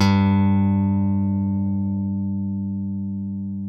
ALEM PICK G2.wav